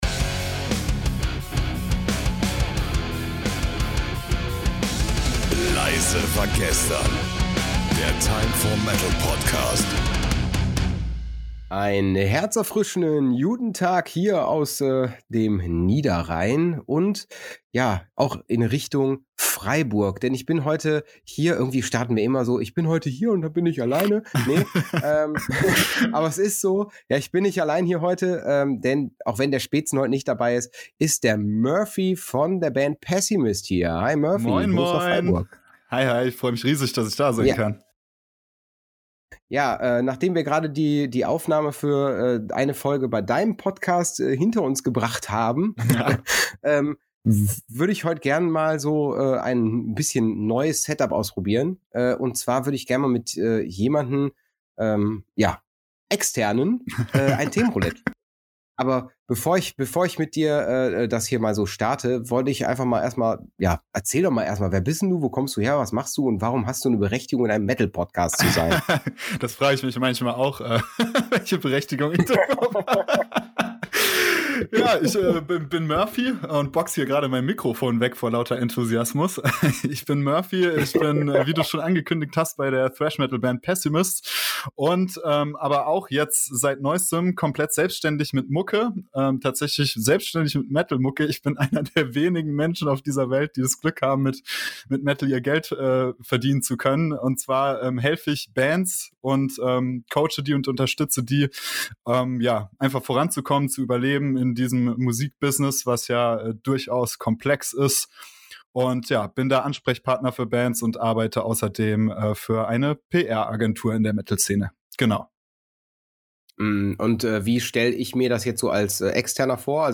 Themenroulette - Die Spielregeln Pro Folge werden per Zufallsgenerator drei Themen gewählt, welche dann von den beiden Moderatoren innerhalb von exakt zehn Minuten behandelt werden. Nach zehn Minuten wird die Unterhaltung gestoppt und das nächste Thema wird behandelt.